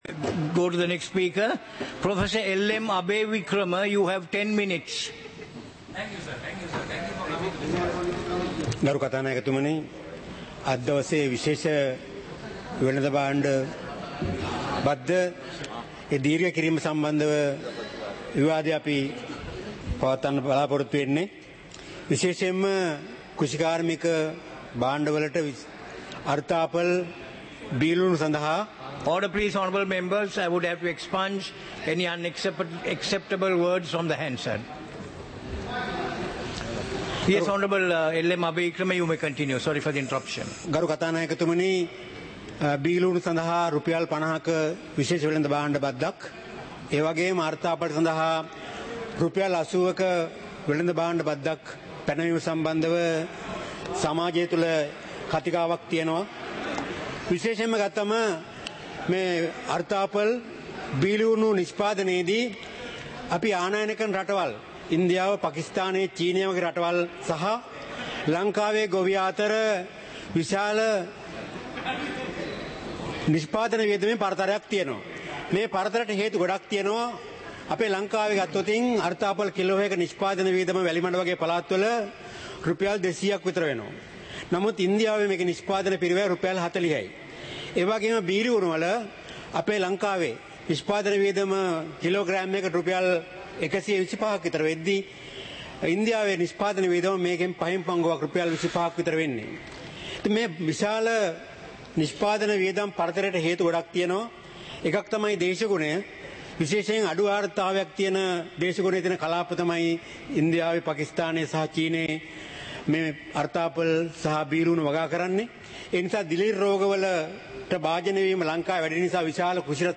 இலங்கை பாராளுமன்றம் - சபை நடவடிக்கைமுறை (2026-02-18)